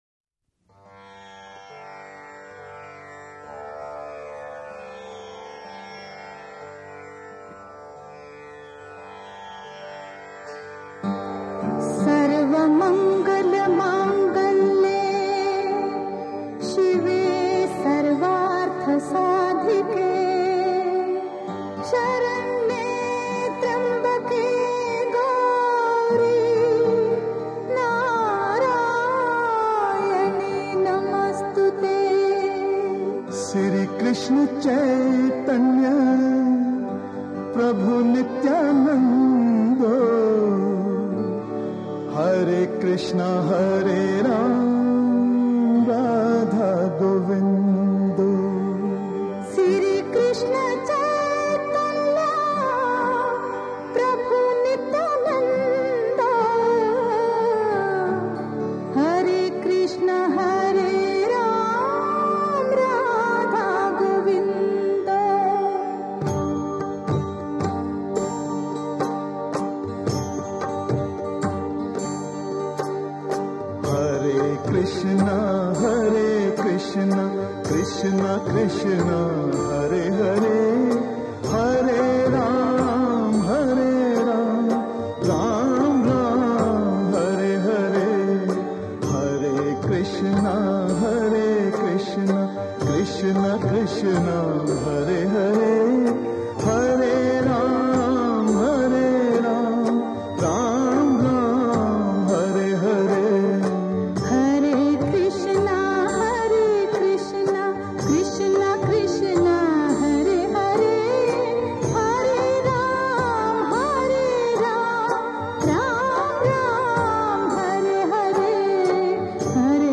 Chorus, Traditional Bhajan